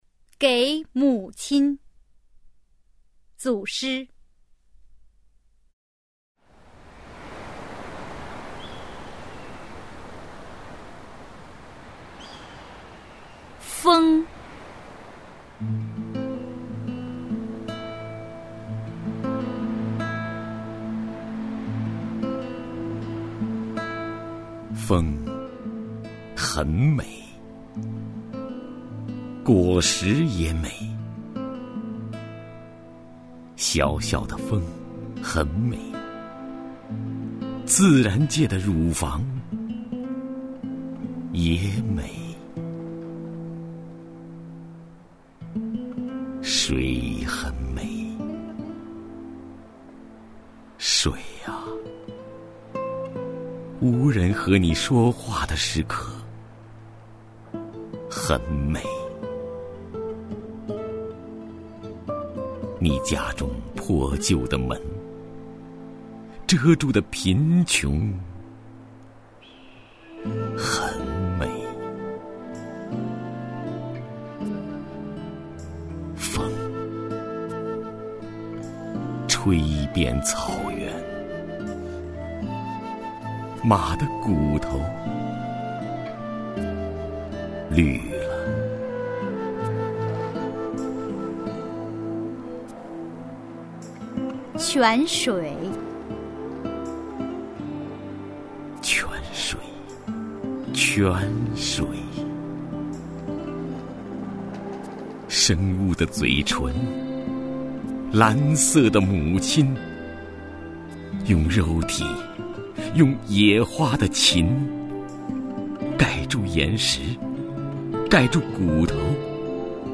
徐涛朗诵：《给母亲（组诗）》(海子)　/ 海子
名家朗诵欣赏 徐涛 目录